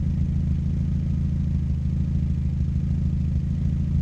i4_02_idle.wav